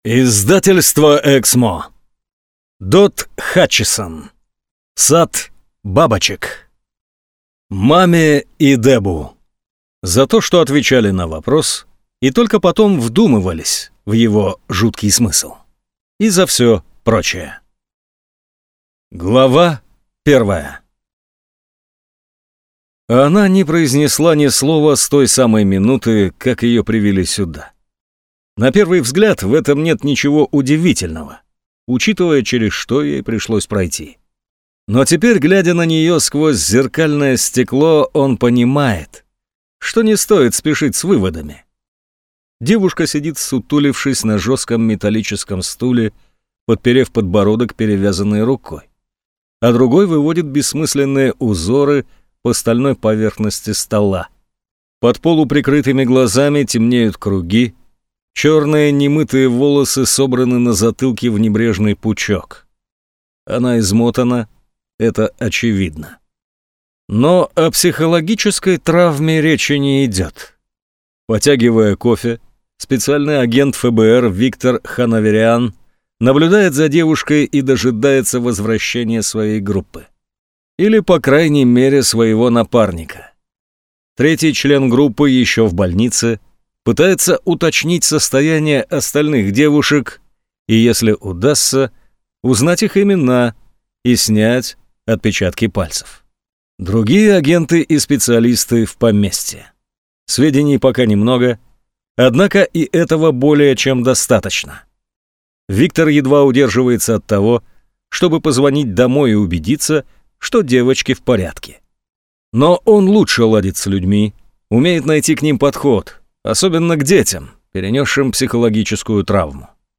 Аудиокнига Сад бабочек | Библиотека аудиокниг
Прослушать и бесплатно скачать фрагмент аудиокниги